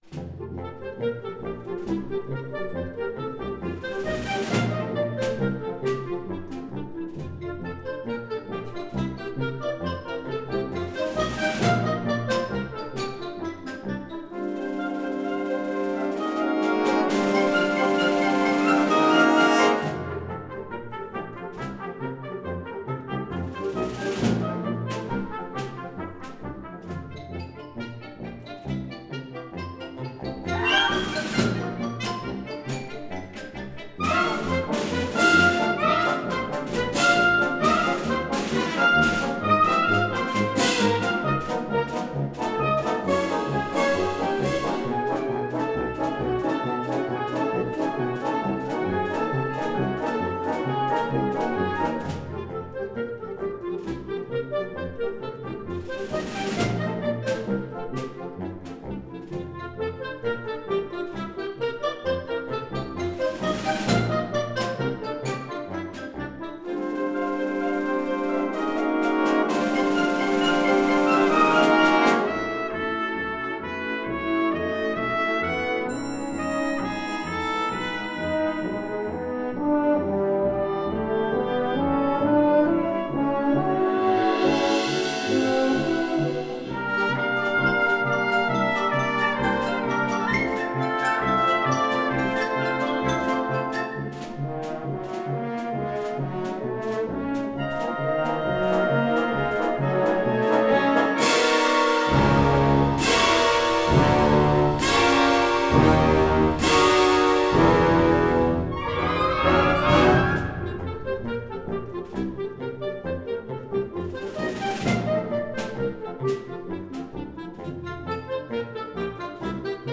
Gattung: Konzertwerk für Symphonic Band
Besetzung: Blasorchester